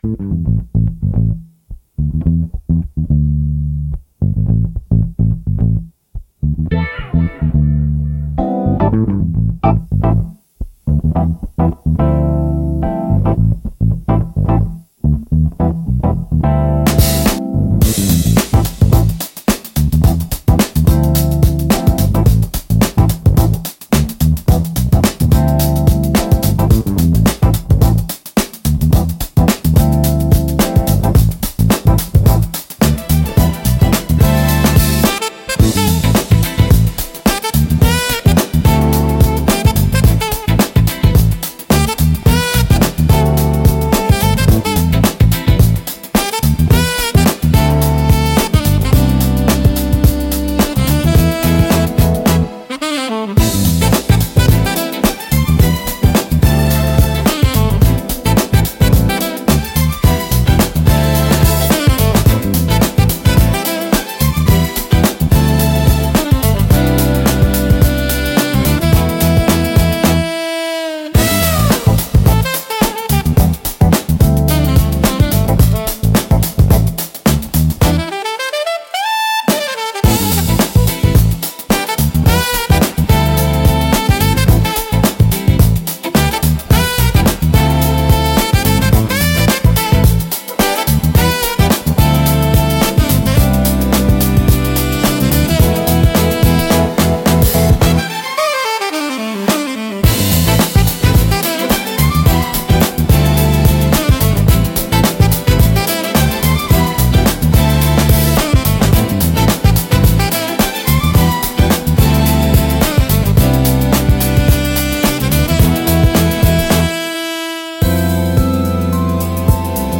落ち着きつつも躍動感があり、聴く人の気分を盛り上げつつリラックスさせる効果があります。